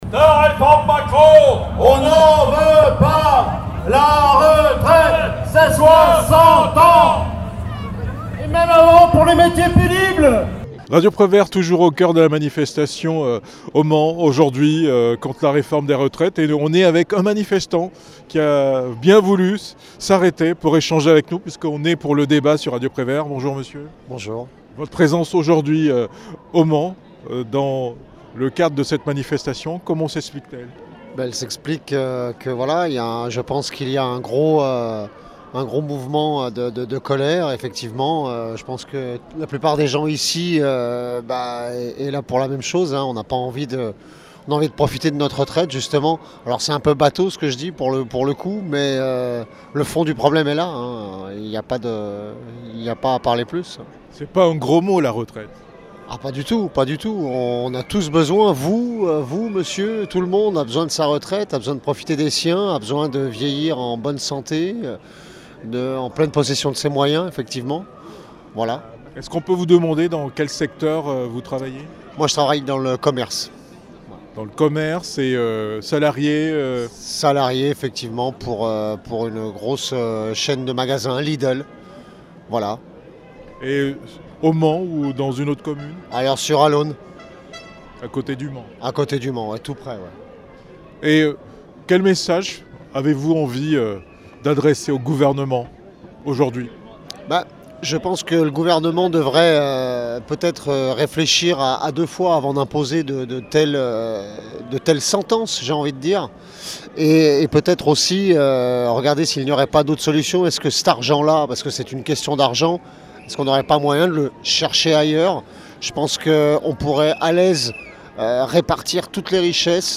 Manifestation contre la réforme des retraites au Mans
Manifestation contre la réforme des retraites - Homme anonyme